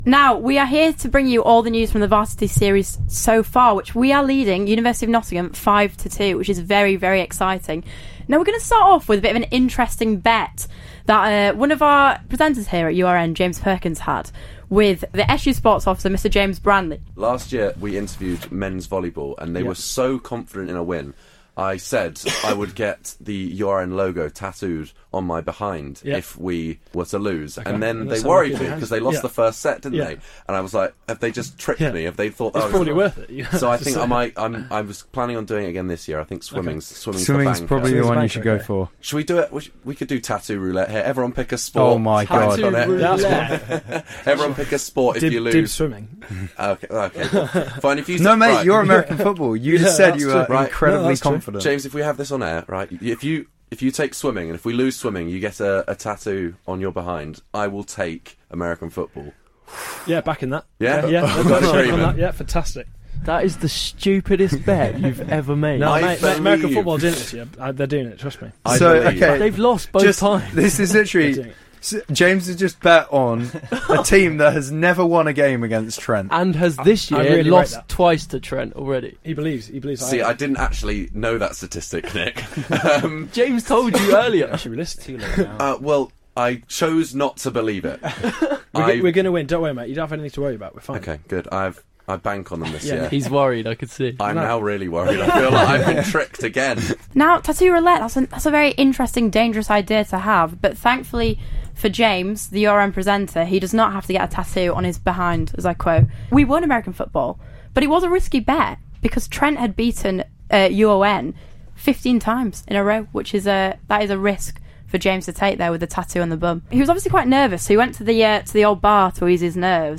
Featuring drunken American Football commentary